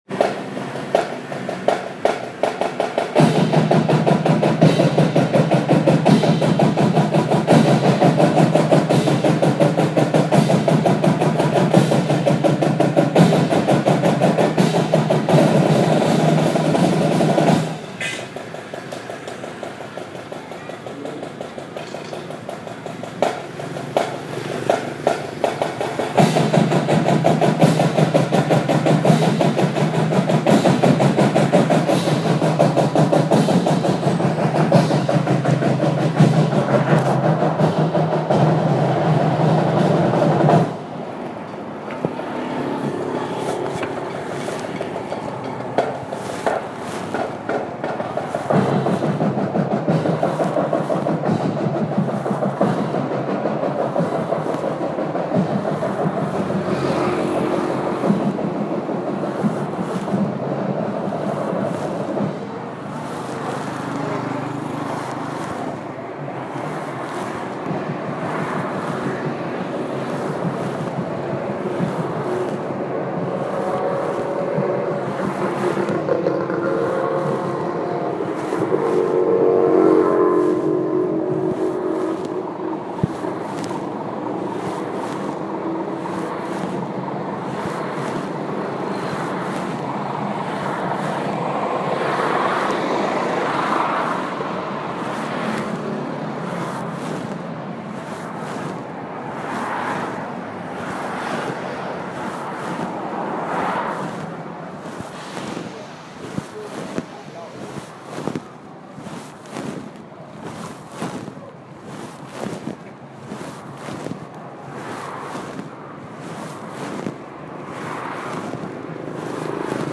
내 오른쪽에는 먹색 시간에도 집에 돌아가지 않은 어느 학생들만 강조해주는 형광펜 같은 불빛 아래에서 학생들이 쿵짝쿵짝 복작복작 북을 치고 장구를 치고 있었다.